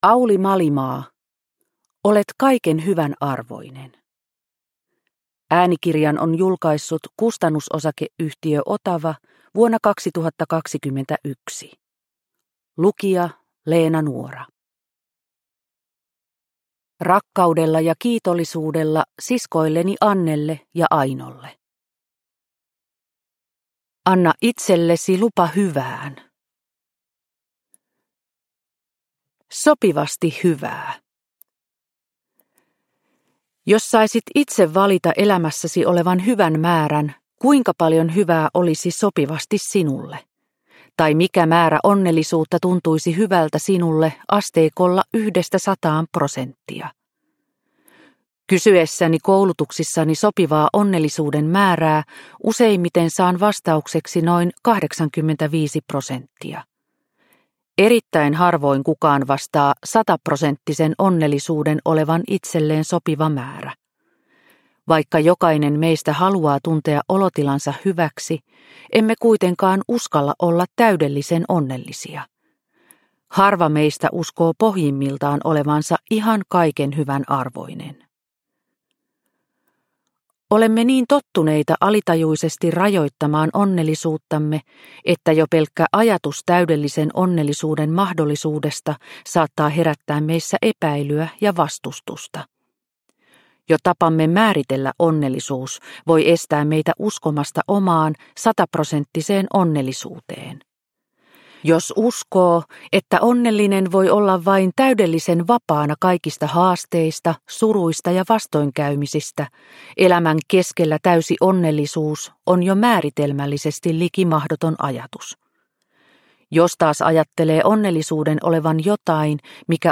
Olet kaiken hyvän arvoinen – Ljudbok – Laddas ner